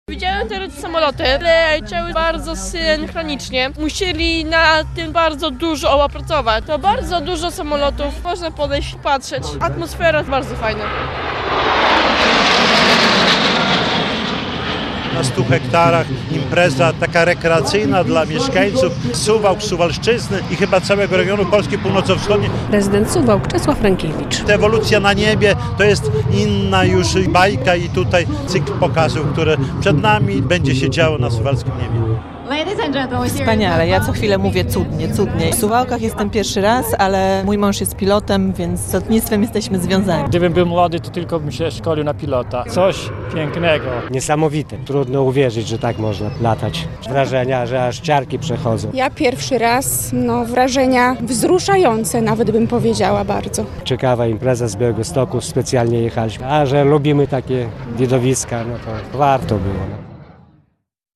Trzecia edycja pikniku Odlotowe Suwałki Air Show - relacja